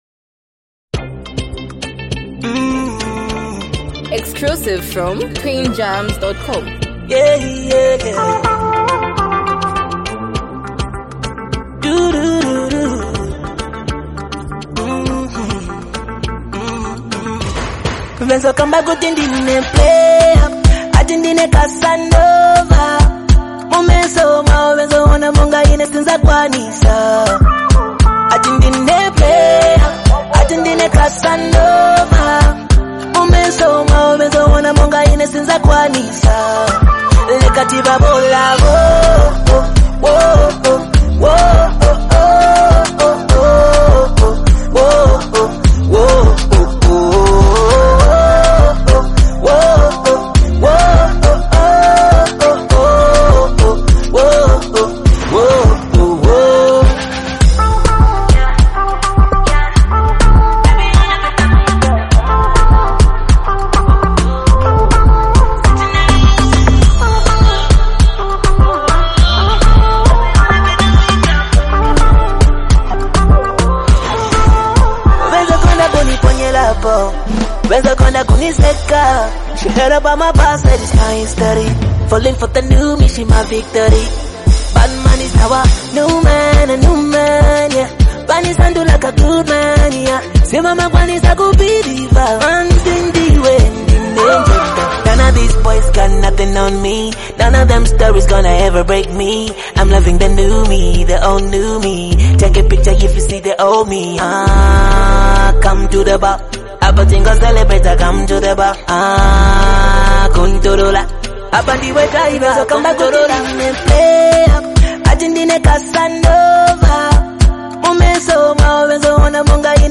catchy and energetic